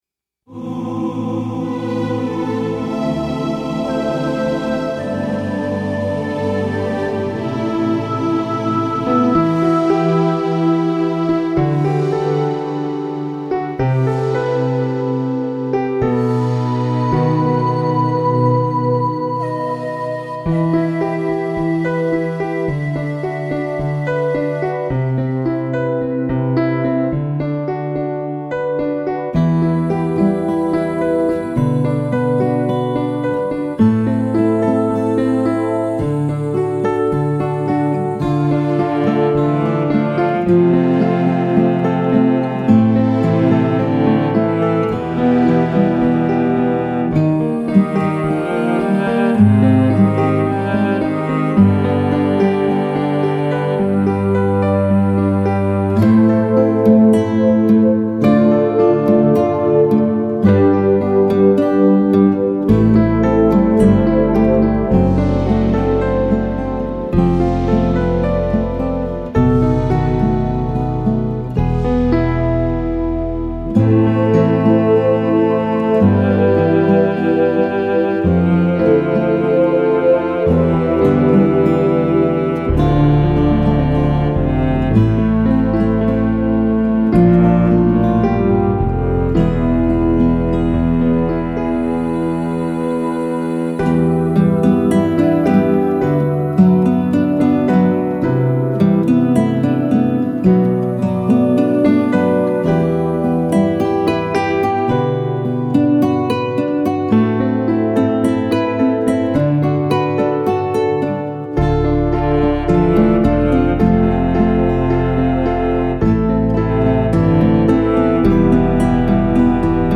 This medley of lush song arrangements convey peacefulness.